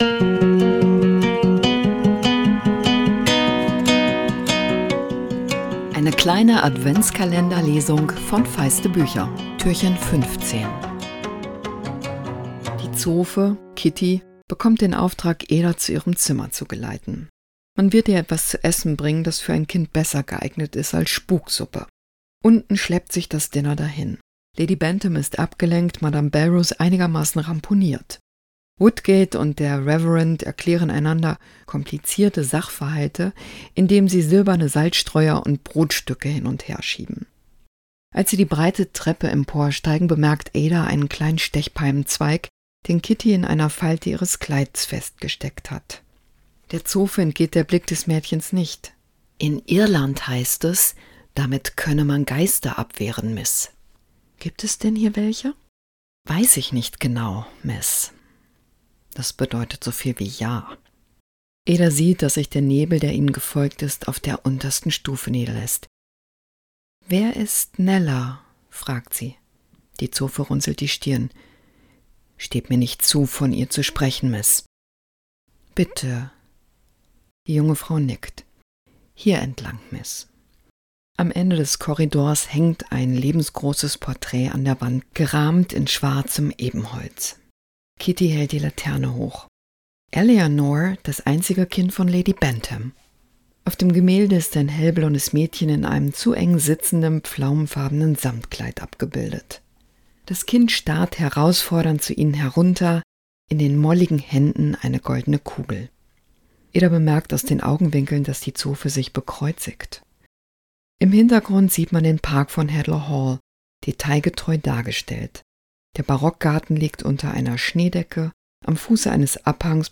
Adventskalender-Lesung 2024! Jess Kidd nimmt euch mit ins Leben des Waisenmädchens Ada Lark, die herausfinden will, was es mit dem ungewöhnlichen Nebel auf sich hat...